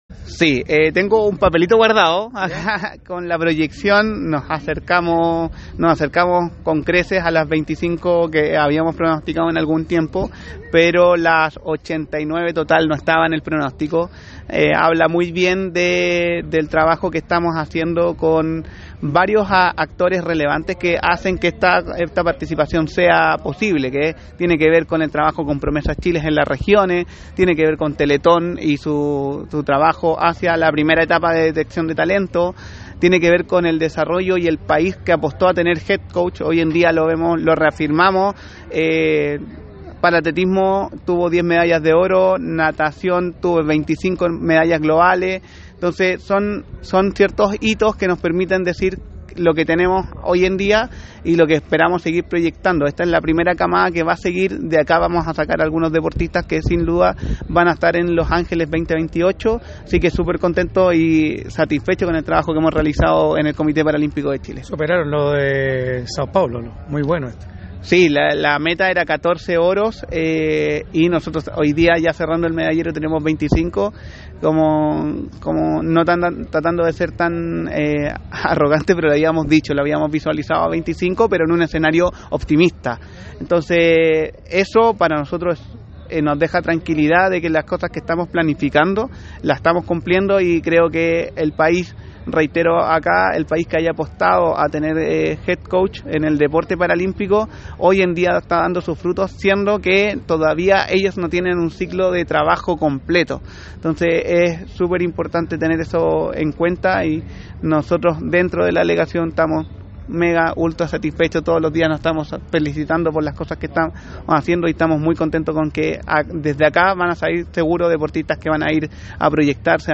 En conversación con ADN TOP